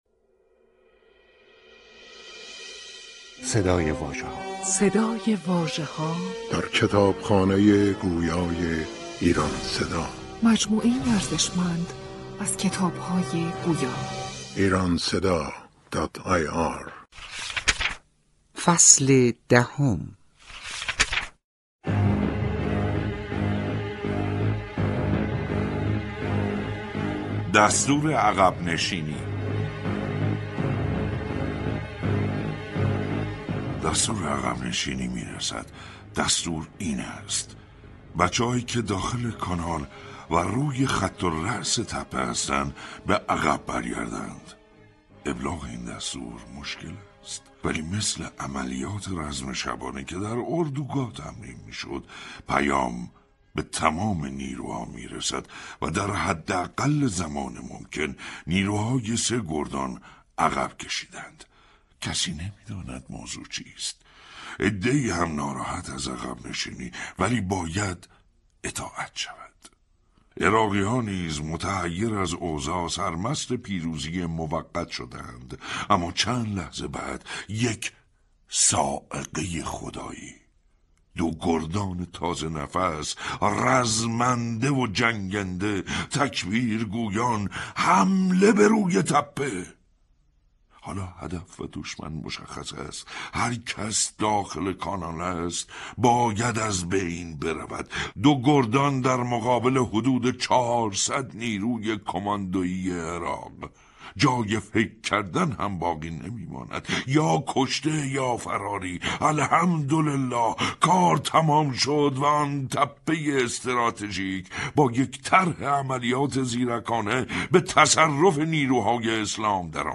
کتاب گویای «سینای شلمچه» منتشر شد